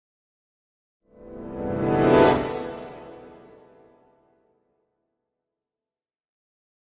Piano Reversed Fast Ascending Tension - Light